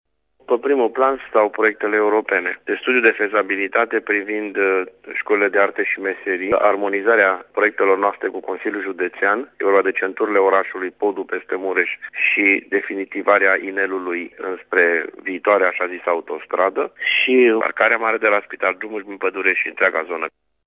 Primarul municipiului Tirgu-Mureş, Dorin Florea: